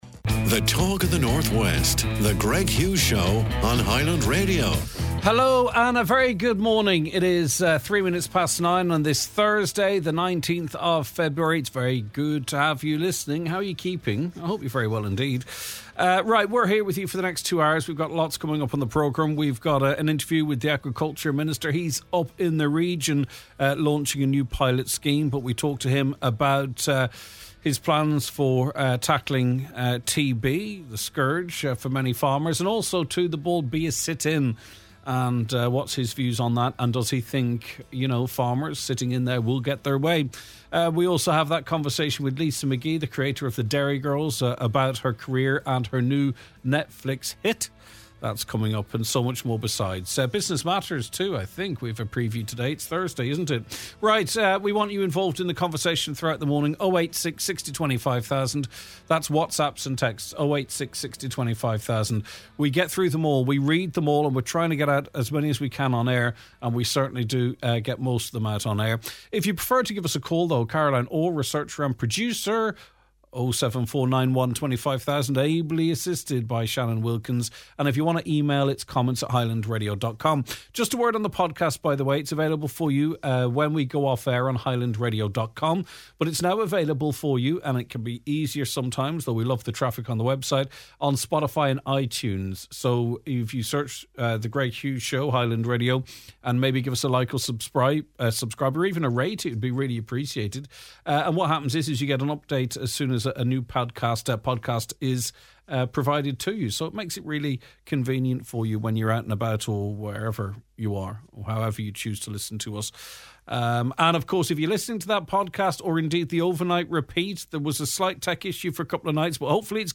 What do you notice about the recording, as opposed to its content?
Live Music & Business